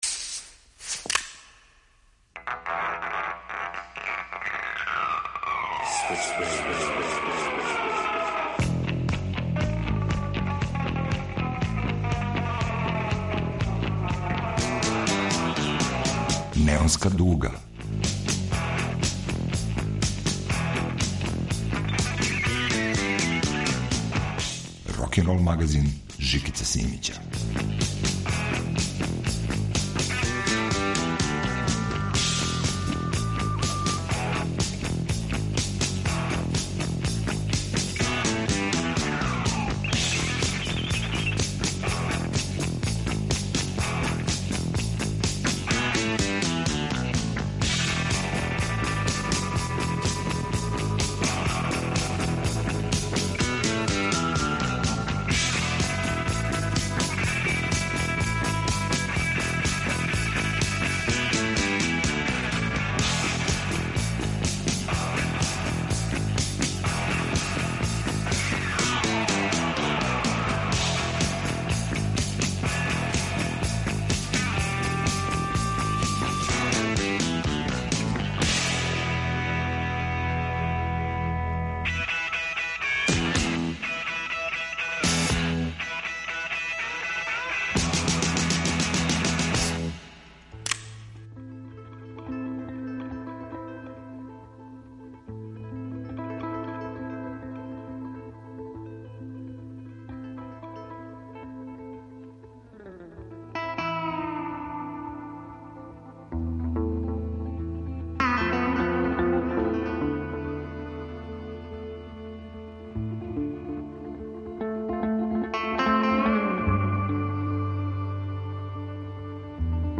Ovog puta od ambijentalne, instumentalne muzike, preko klasičnog roka, do najnovije produkcije.